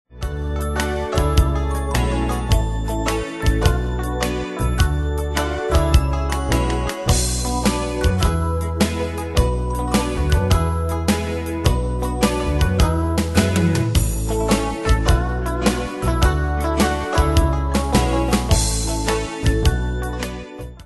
Style: Retro Ane/Year: 1967 Tempo: 105 Durée/Time: 2.31
Danse/Dance: Ballade Cat Id.
Pro Backing Tracks